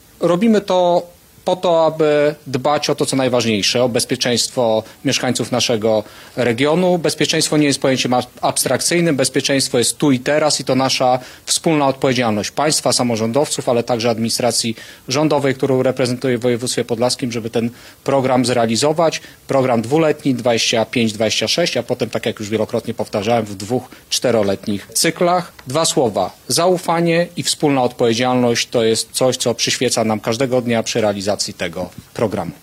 Wojewoda podlaski Jacek Brzozowski.